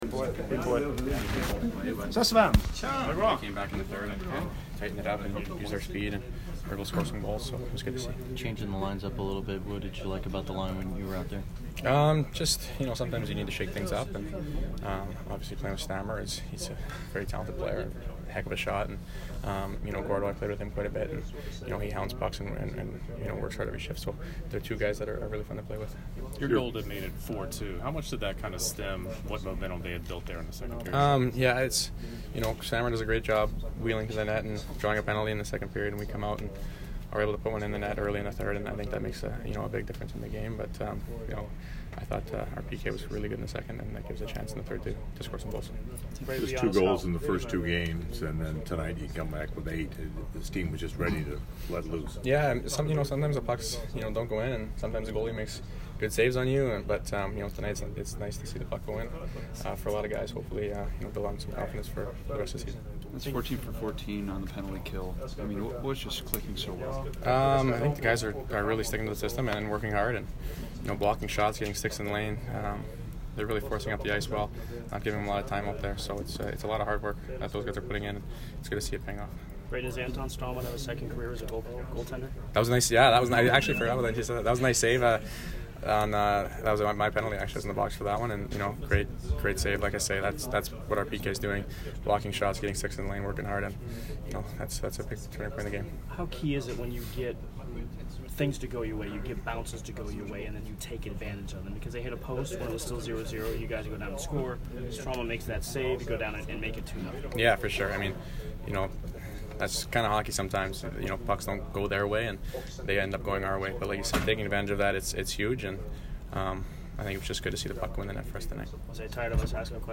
Brayden Point post-game 10/13